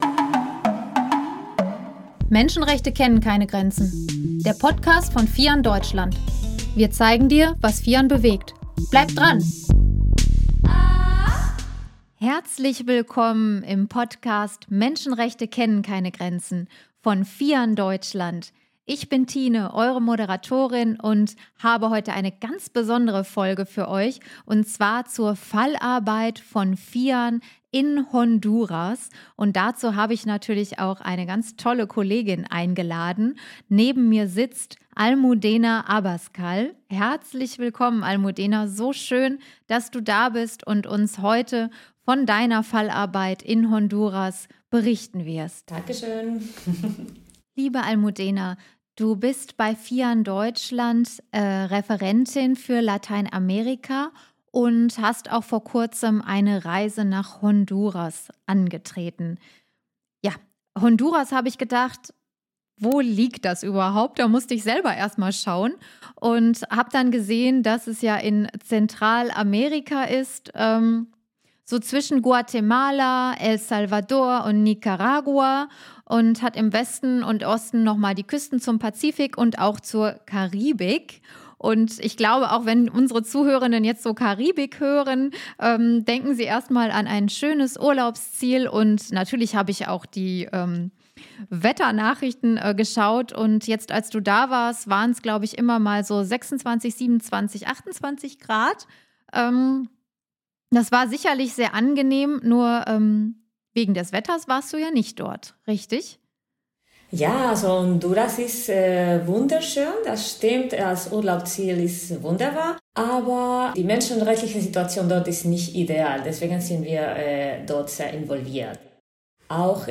In Honduras werden Menschenrecht durch den Anstieg des Meeresspiegels und die Agrarindustrie verletzt. Welche Rolle spielt Deutschland dabei? Ein Interview